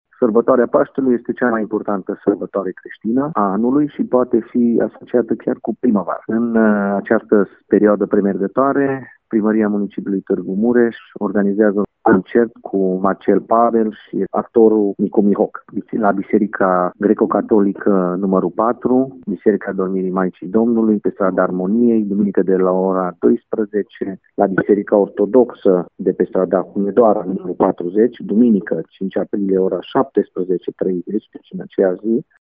Viceprimarul Municipiului Tîrgu-Mureș, Claudiu Maior: